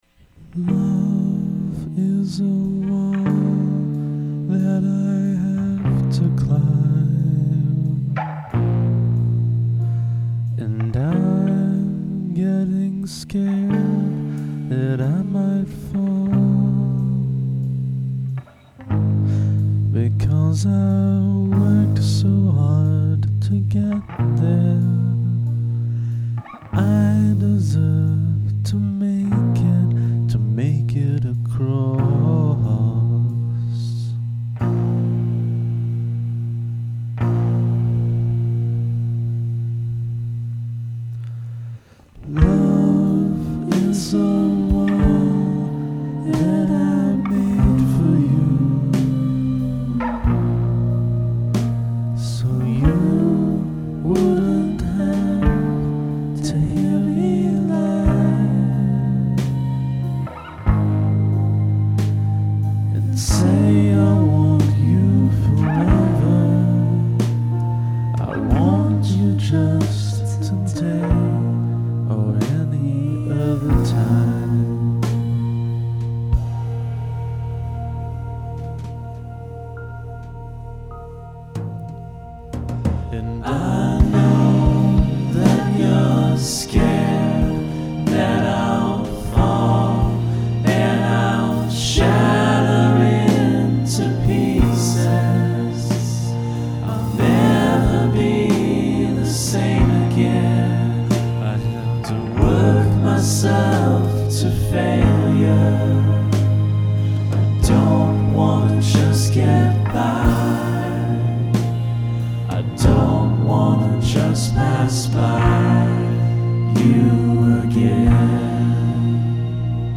This song is short and quiet and unresolved.
Nice to hear you with slow, rich vocals.....really nice.